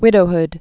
(wĭdō-hd)